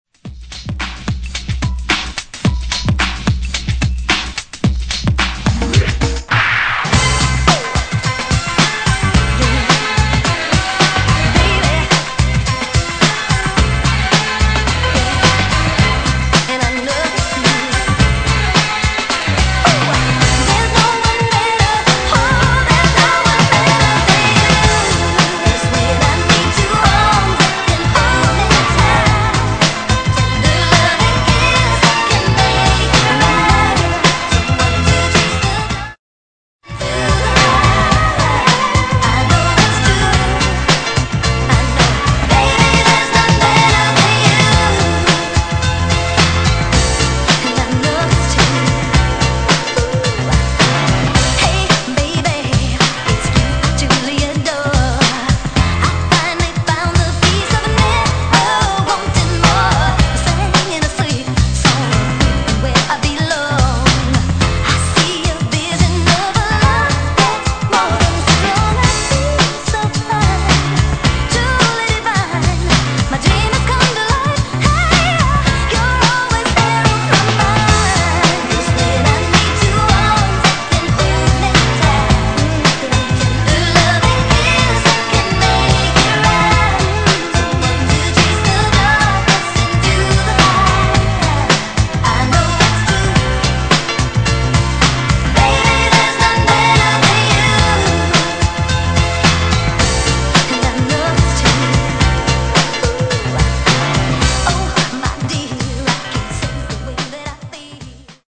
自身今まで聞いた事がなかったくらいレアですが、イントロ長くかなり使いやすいremixとなっています。